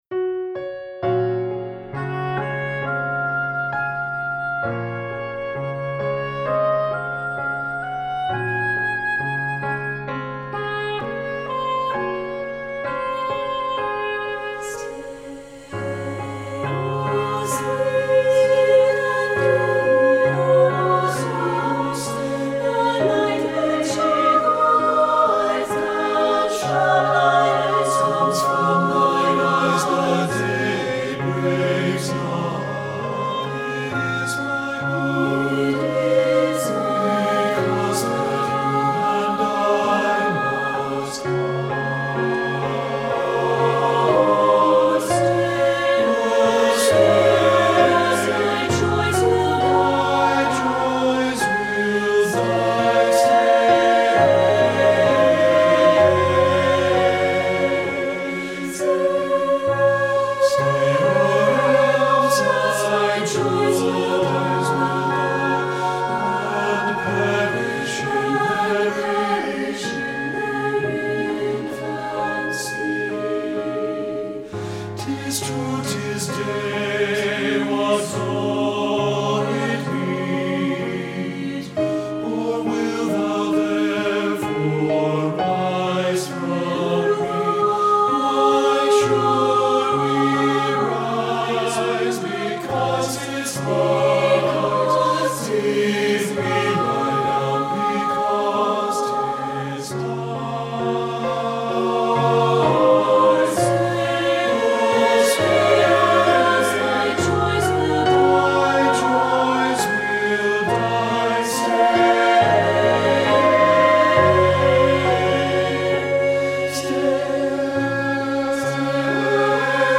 • Soprano
• Alto
• Tenor
• Bass
• Oboe (or English Horn)
• Keyboard
Studio Recording
Ensemble: Mixed Chorus
Accompanied: Accompanied Chorus